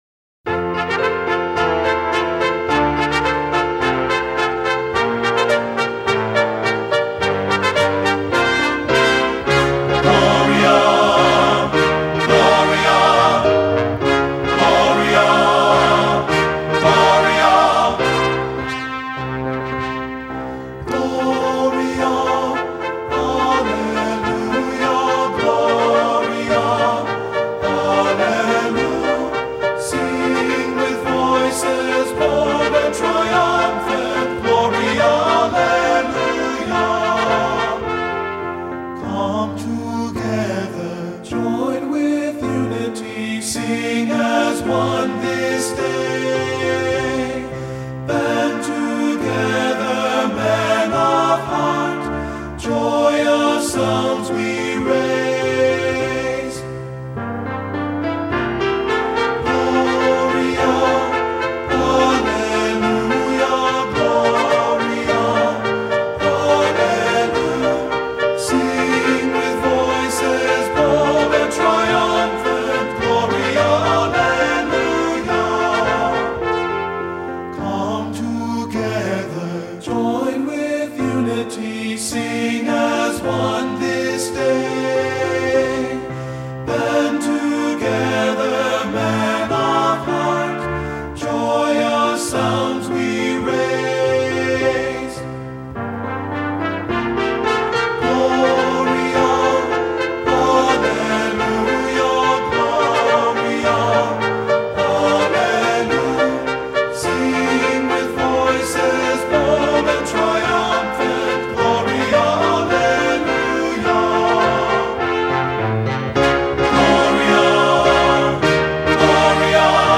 Voicing: TB Collection